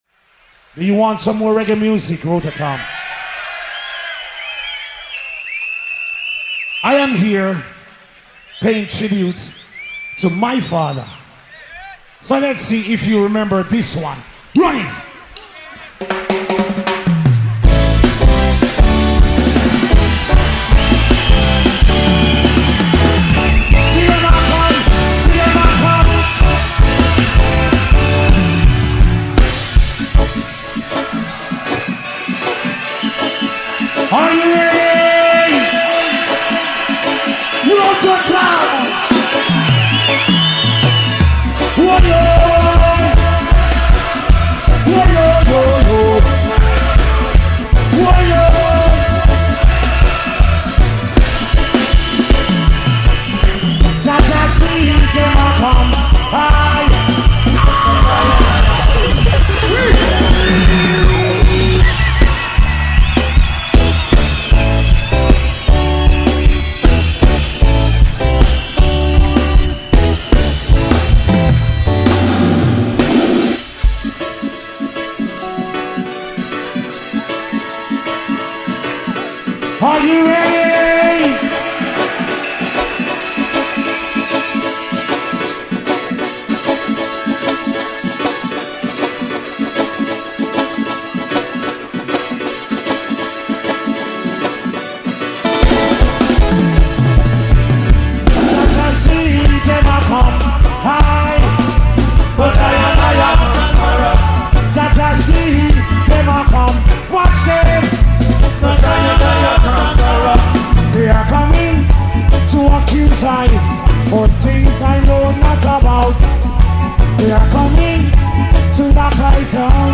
🎙 Podcast – Intervista a Kenyatta Hill | Artista a la Vista · Rototom Sunsplash 2025 📻 Radio Città Aperta · Conduce
Artista-a-la-Vista-Intervista-Kenyatta-Hill-Rototom-2025.mp3